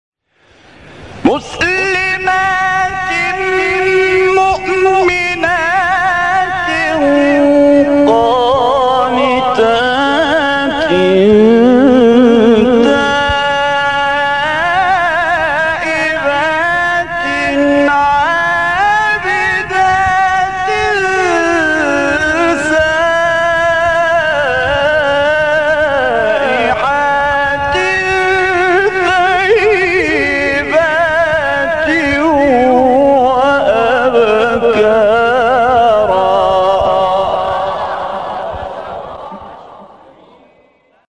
گروه فعالیت‌های قرآنی: مقاطعی صوتی از قاریان برجسته جهان اسلام که در مقام رست اجرا شده‌اند، ارائه می‌شود.
مقام رست